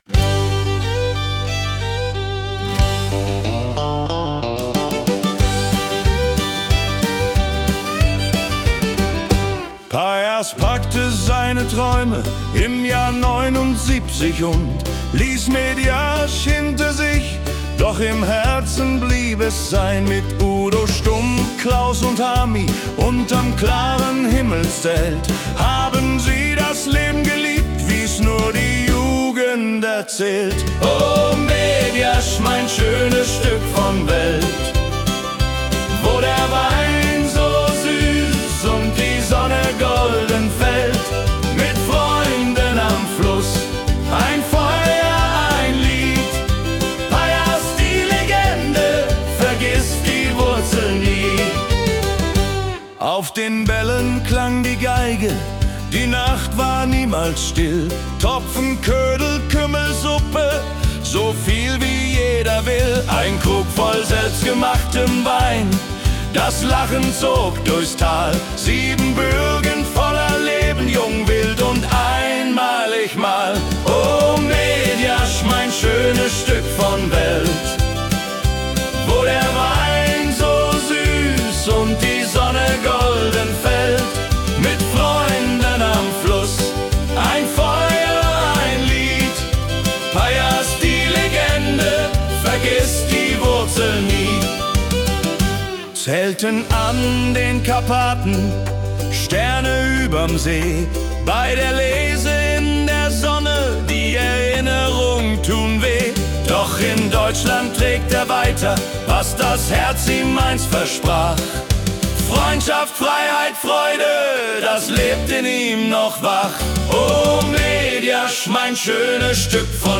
Country
• KI-generierte Melodie